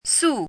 “苏”读音
苏字注音：ㄙㄨ/ㄙㄨˋ
国际音标：su˥;/su˥˧
sù.mp3